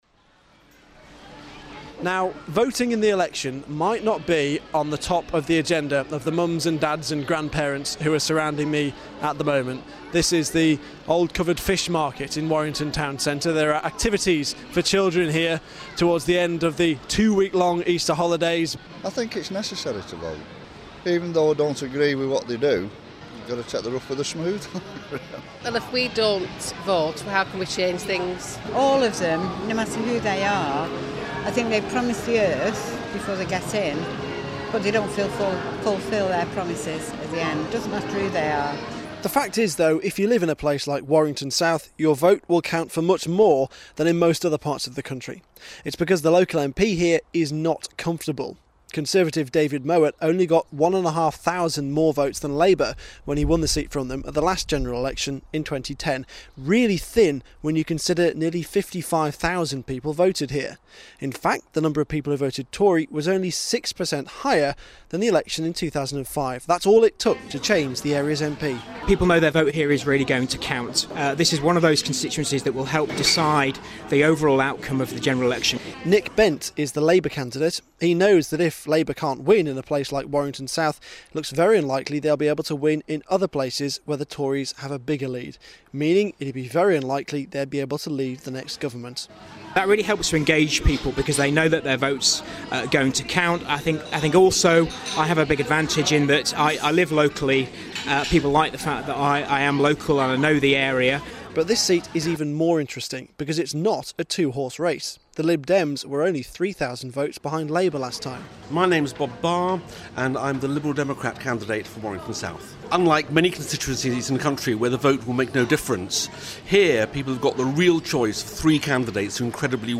The candidates in Warrington South talking about how close their race is.